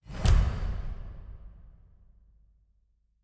Special Click 12.wav